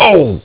Worms speechbanks
Ow3.wav